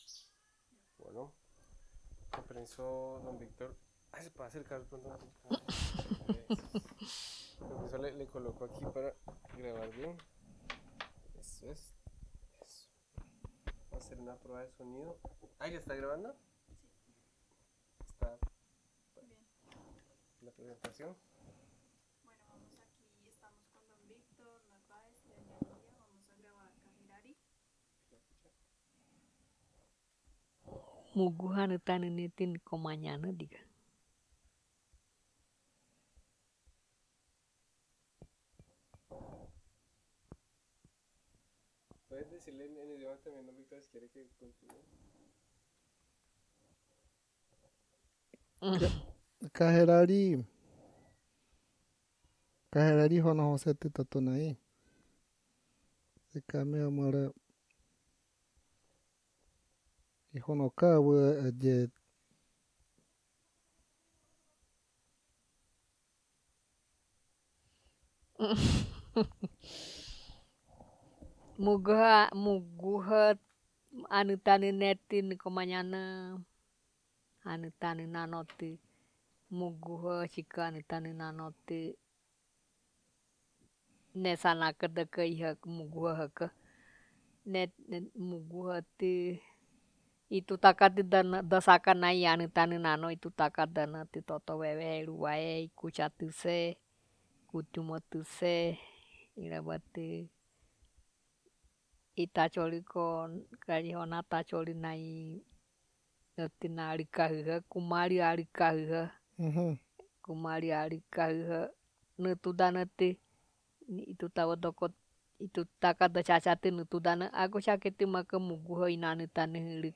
Narración Kaherari.